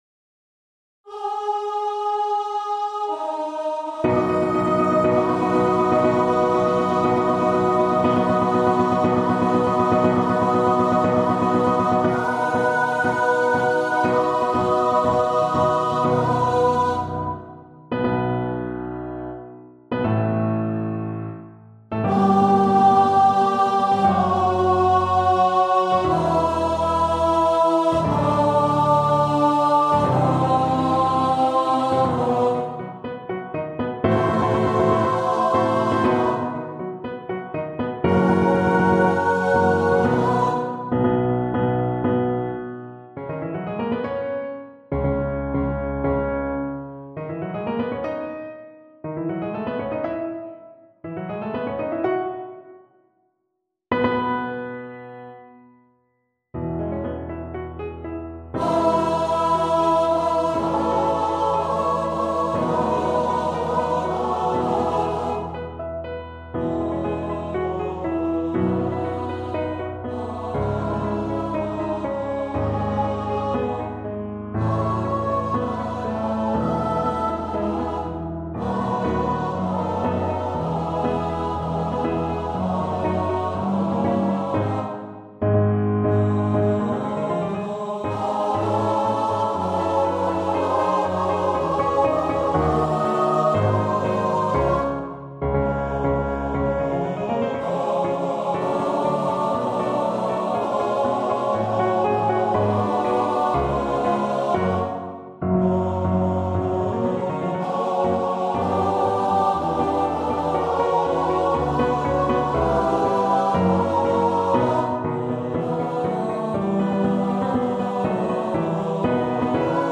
Free Sheet music for Choir
Classical (View more Classical Choir Music)